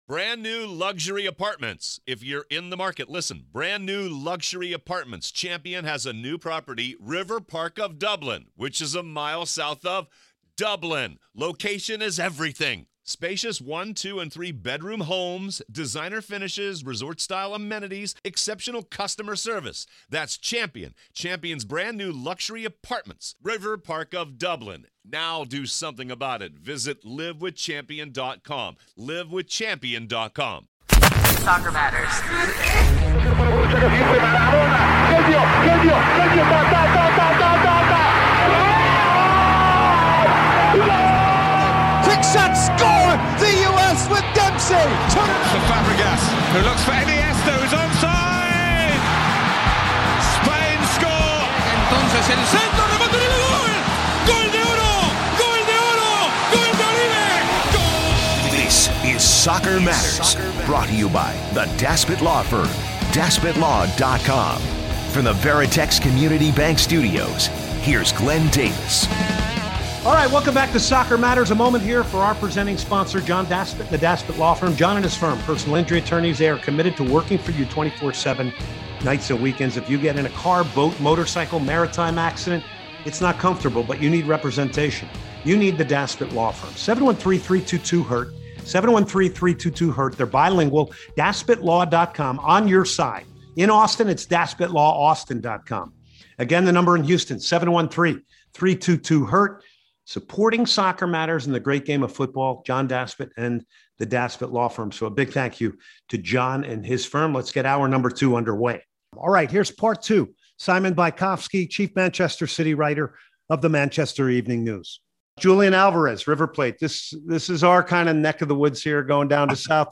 in-depth interview